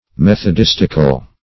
Search Result for " methodistical" : The Collaborative International Dictionary of English v.0.48: Methodistic \Meth`o*dis"tic\, Methodistical \Meth`o*dis"tic*al\, a. Of or pertaining to methodists, or to the Methodists.